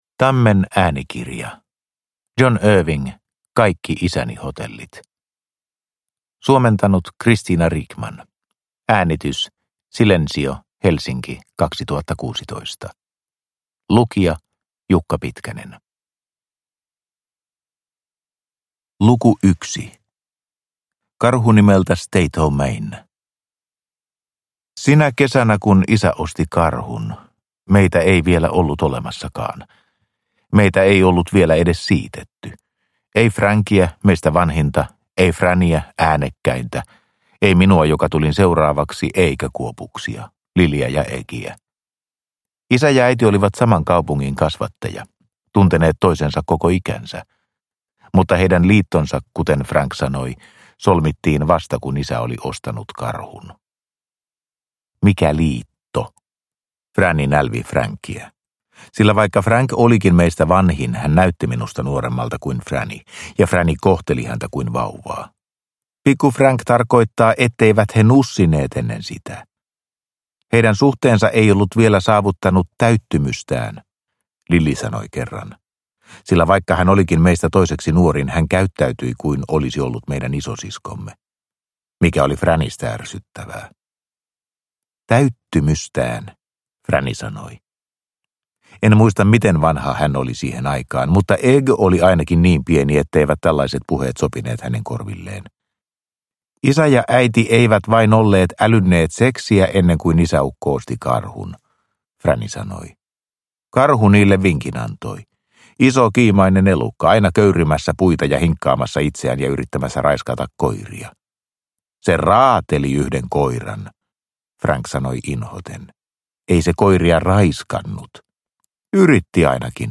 Kaikki isäni hotellit – Ljudbok – Laddas ner